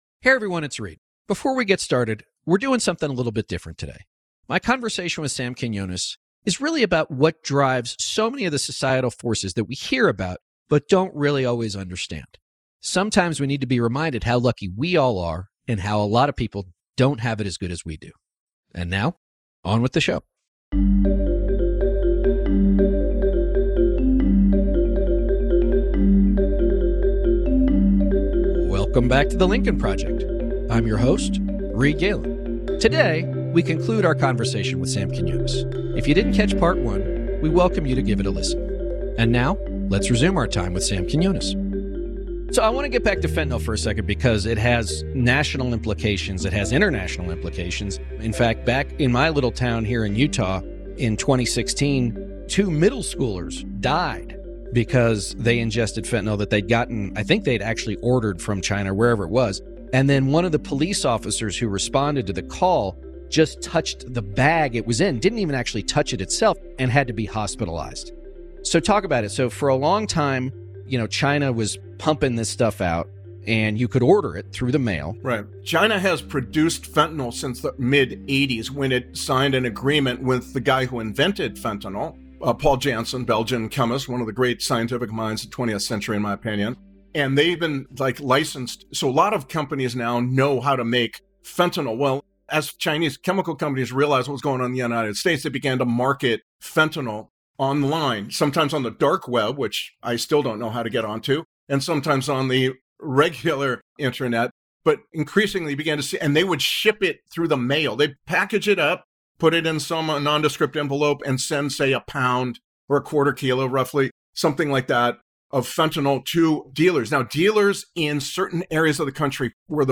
is joined for a two-part conversation by Journalist and Author Sam Quinones. In “Part 2”, they discuss how corporations prey on addiction to legally market their products, how communities that have recovered from large scale drug addiction are rich in “new coal” as a resource, and how the opioid crisis touches so many other non-drug issues in American society.